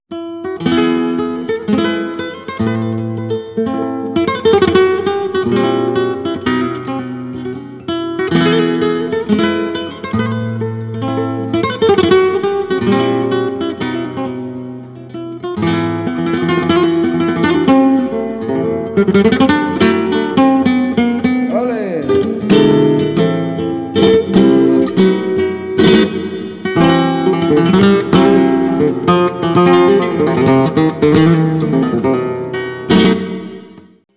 Tientos / 7 falsetas